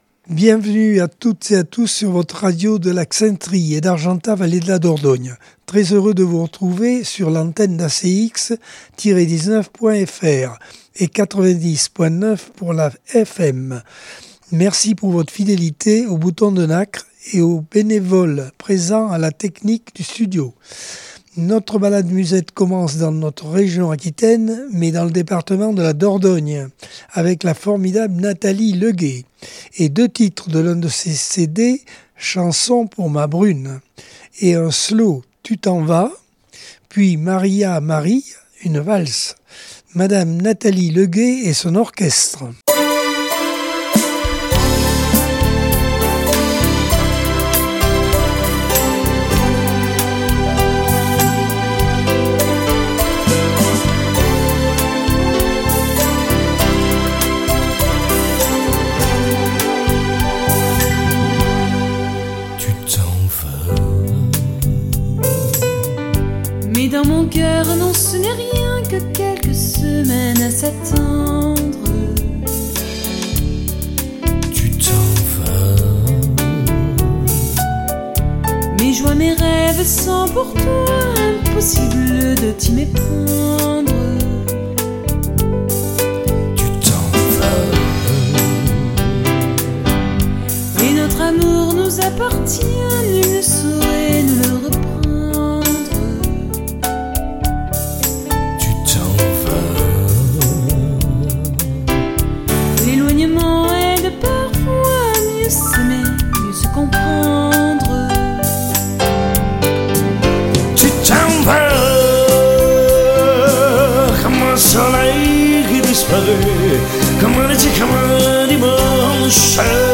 Accordeon 2025 sem 44 bloc 1 - Radio ACX